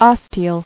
[os·te·al]